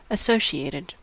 Ääntäminen
Ääntäminen US Haettu sana löytyi näillä lähdekielillä: englanti Käännös Adjektiivit 1. liittyvä Associated on sanan associate partisiipin perfekti.